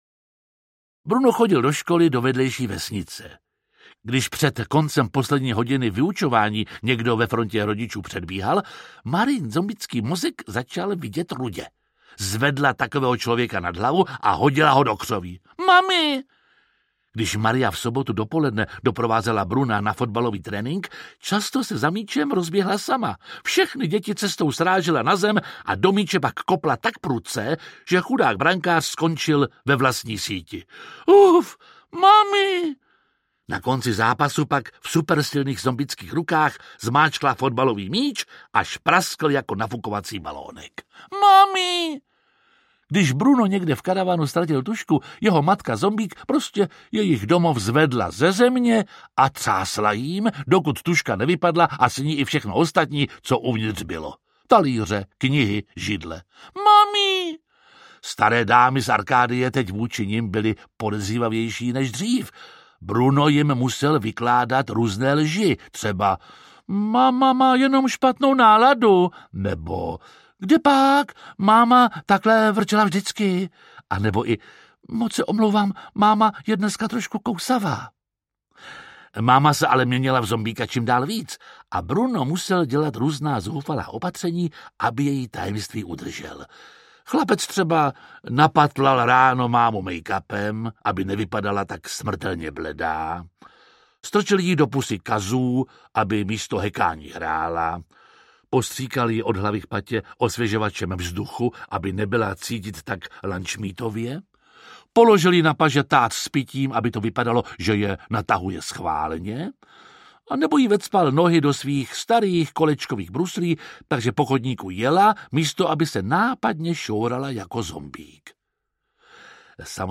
Audiobook
Read: Jiří Lábus